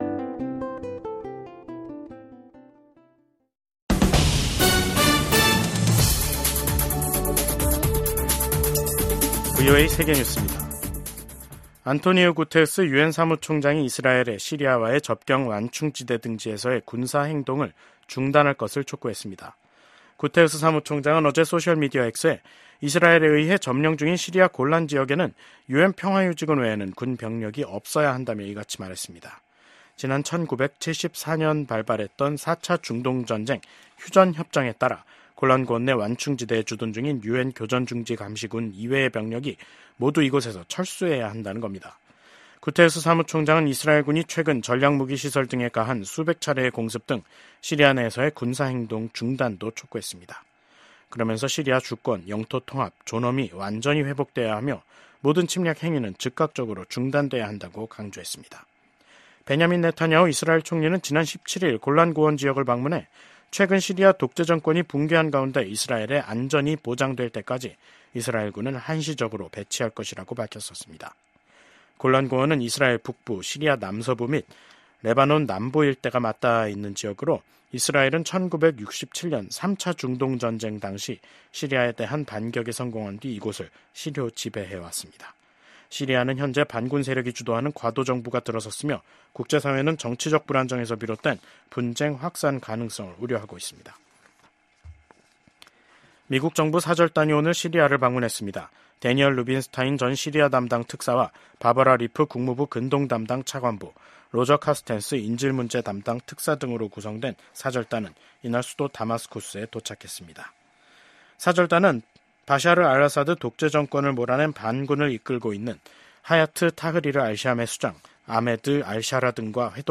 VOA 한국어 간판 뉴스 프로그램 '뉴스 투데이', 2024년 12월 20일 2부 방송입니다. 미국 국무부는 우크라이나 전쟁에 북한군을 투입한 김정은 국무위원장의 국제형사재판소(ICC) 제소 가능성과 관련해 북한 정권의 심각한 인권 유린 실태를 비판했습니다. 북한이 한국의 대통령 탄핵 정국과 미국의 정권 교체가 겹친 어수선한 연말 정세 속에서 도발적인 행동을 자제하는 양상입니다.